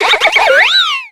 Cri de Laporeille dans Pokémon X et Y.